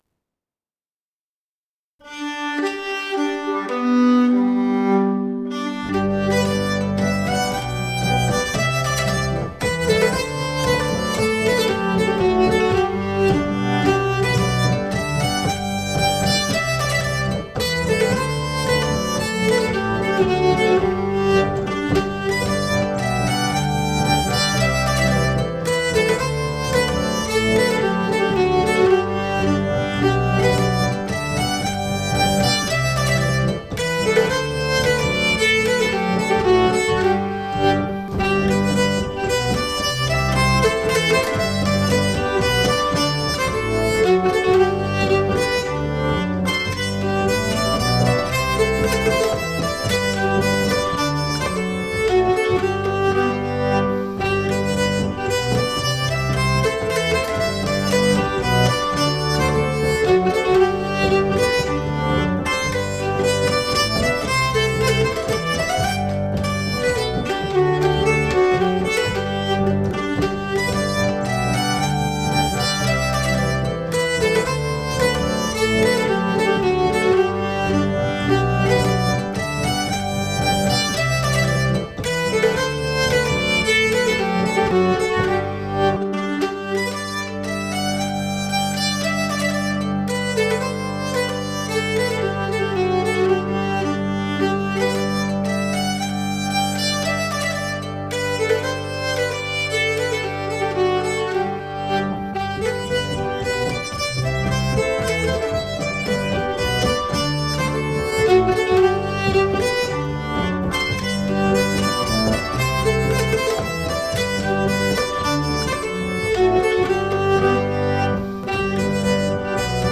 (Ausschnitt)     MP3
Nyckelharpa und Harmonium (Tramporgel / Reed Organ)
Polska aus Hälsingland
Polska from Hälsingland